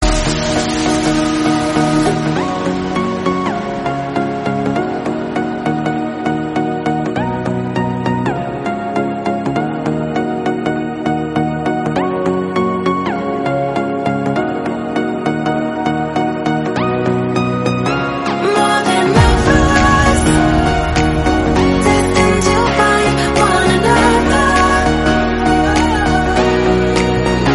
emotional song
delicate arrangement and powerful vocals